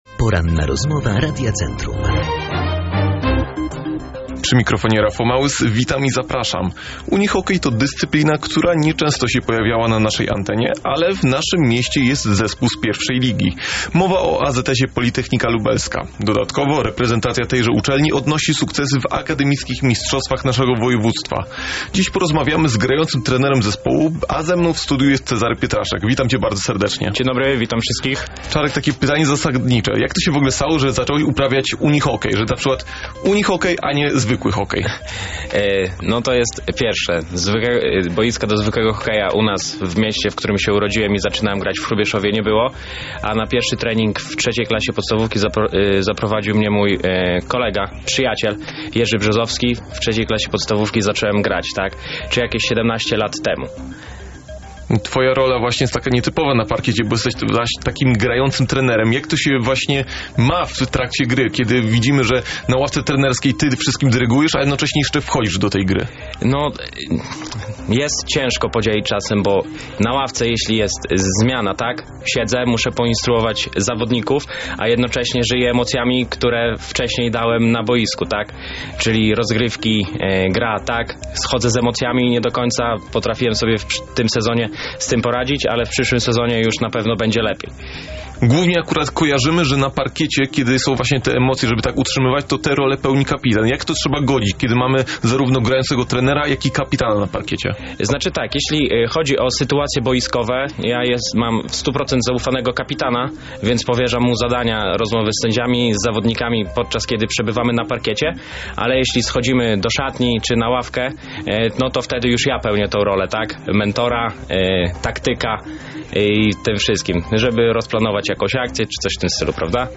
Rozmowa-po-edycja-1.mp3